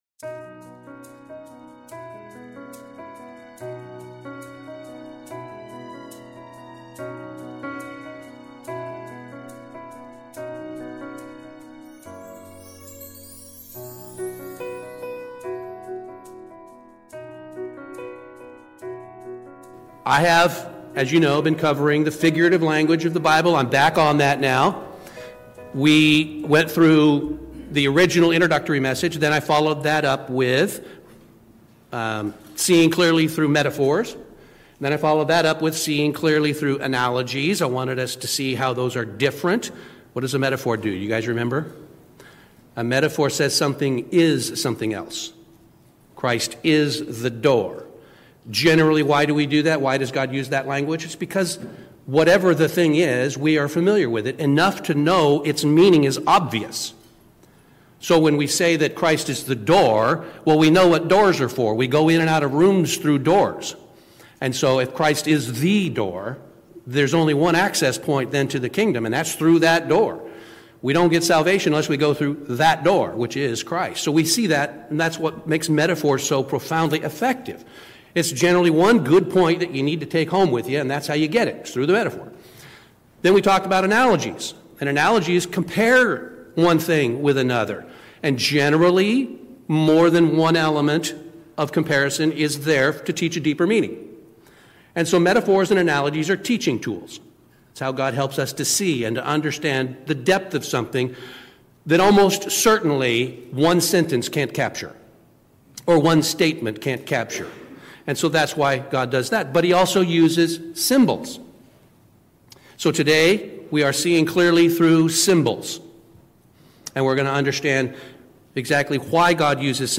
This message explores how God uses symbols as a hands-on way of teaching, helping us see, feel, and live His truth more fully.